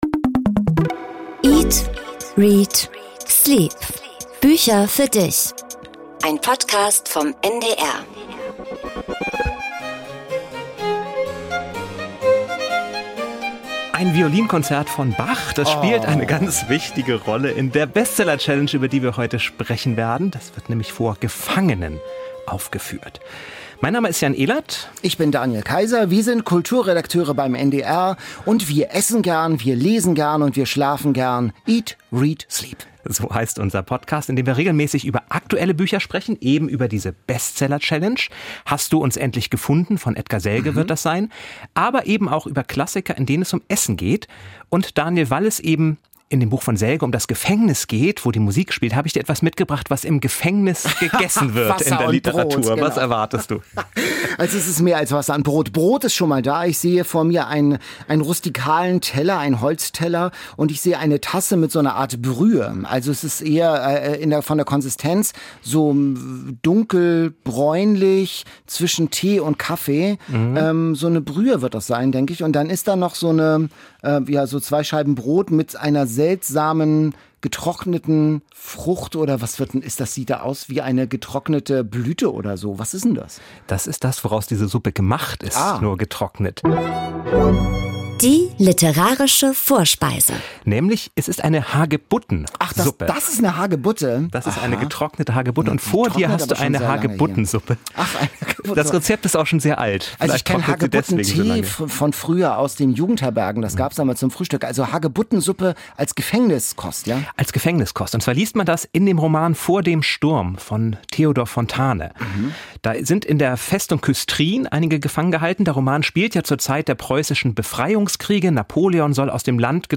Gast Sönke Wortmann, der als Regisseur erfolgreiche Filme wie "Das Wunder von Bern" und "Der bewegte Mann" gedreht hatm erzählt, warum er nun auch Romane schreibt.